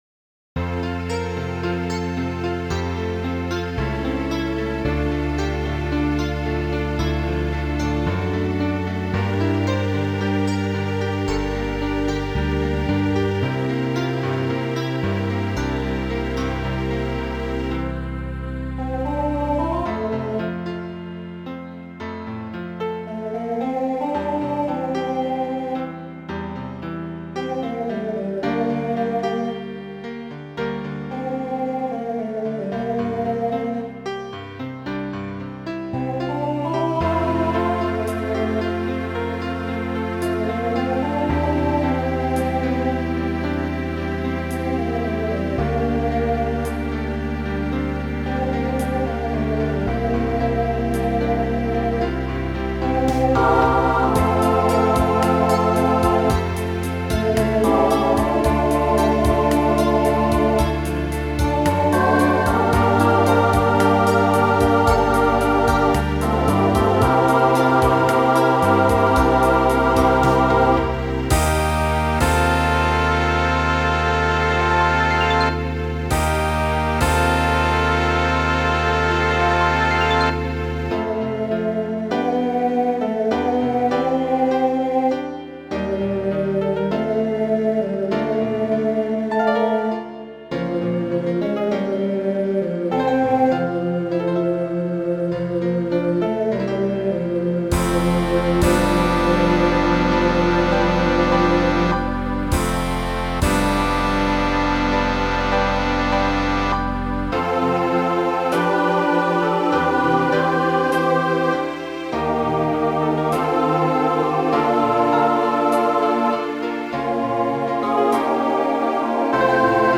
Voicing SATB
Genre Broadway/Film , Pop/Dance
1980s Show Function Ballad